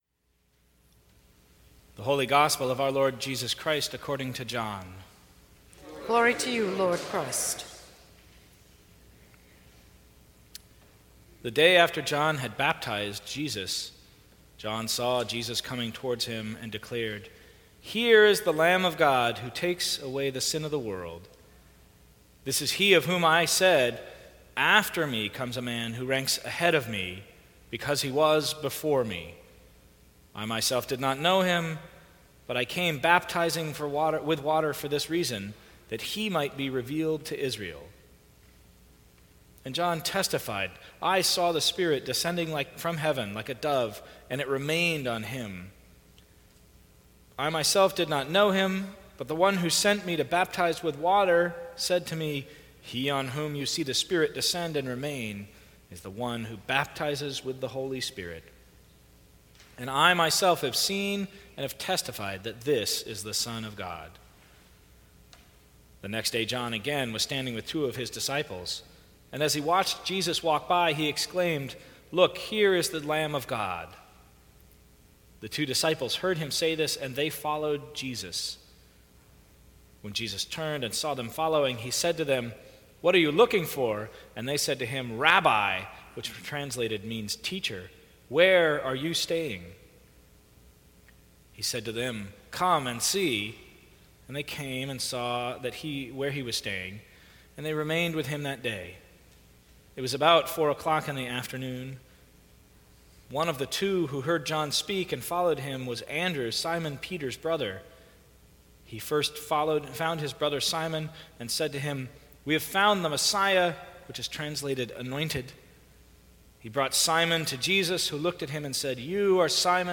Sermons from St. Cross Episcopal Church Telling a bold and courageous story Jan 15 2017 | 00:18:57 1x 00:00 / 00:18:57 Subscribe Share Apple Podcasts Spotify Overcast RSS Feed Share Link Embed